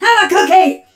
pearl_atk_vo_01.ogg